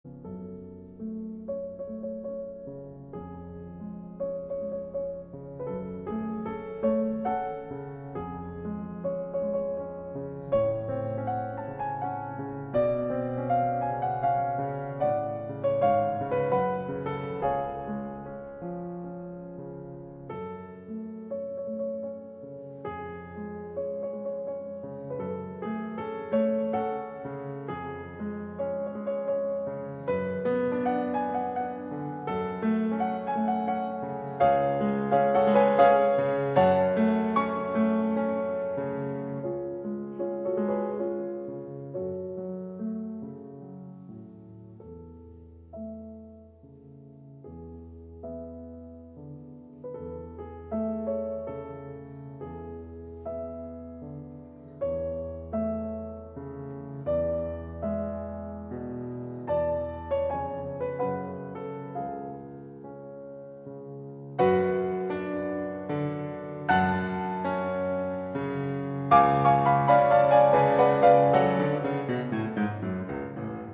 و بعد تلك النوبه تبدأ الألحان المتقطعه محاولات مقاومة تُسكت بكوردات صارخه، وشيئا فشيئا تنجح في المحاوله ويحدث التصالح أخيراً باتفاق الهارموني في آخر كورد، مُلتقطاً انفاسه بعد ذلك
الآن اتضحت الرؤيه وظهر الضوء واستقرت الالحان على مفتاح C# ميجر المشرق